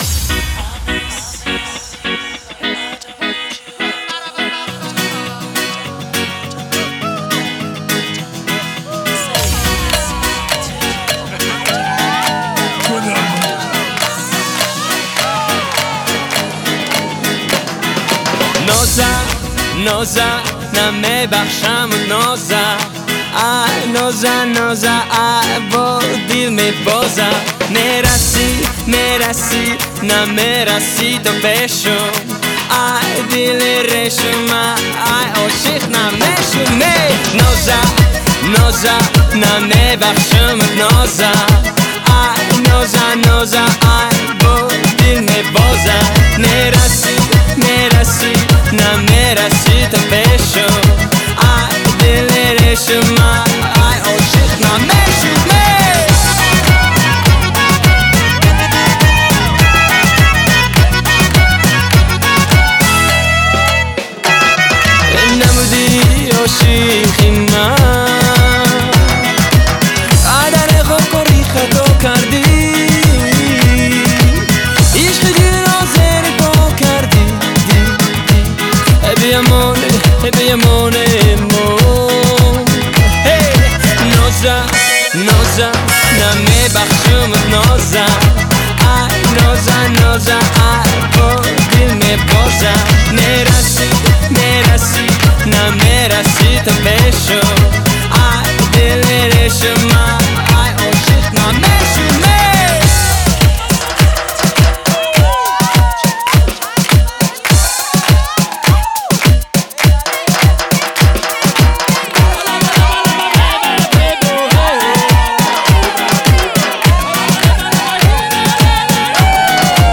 Категория: Эстрада
Зато прикольно парнишка спел ,уже радует))))
Очень позитивый трек!